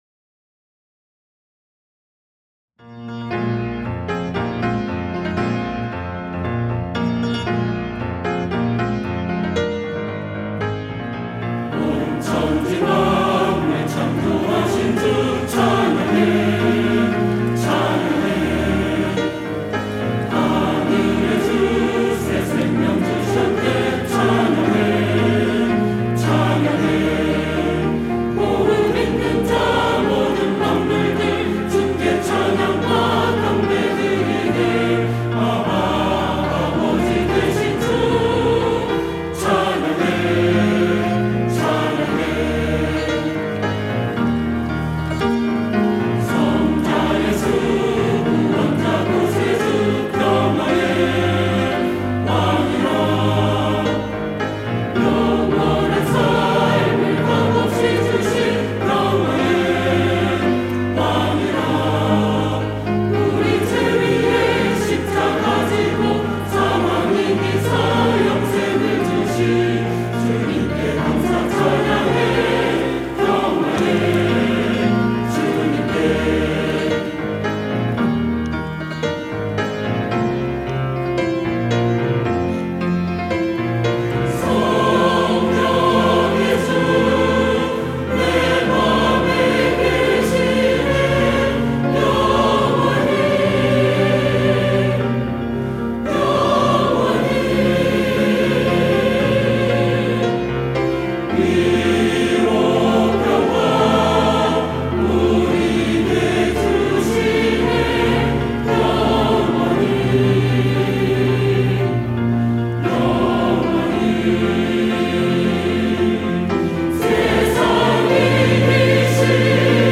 시온(주일1부) - 창조의 하나님
찬양대